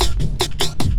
10 LOOPSD2-L.wav